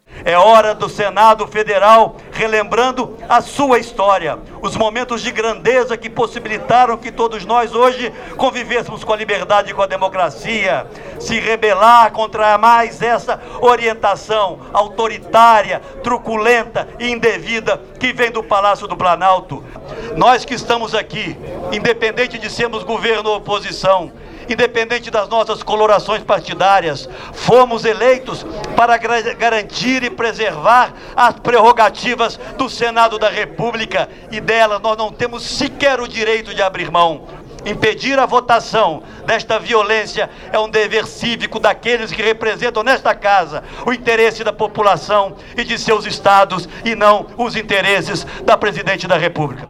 O senador Aécio Neves protestou, ontem, em plenário, contra o rolo compressor do governo federal sobre o Congresso para aprovar as medidas que vao dificultar o lançamento de candidatos adversários ao PT nas eleições presidenciais do ano que vem .
Fala do senador Aécio Neves